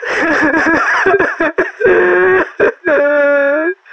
NPC_Creatures_Vocalisations_Robothead [97].wav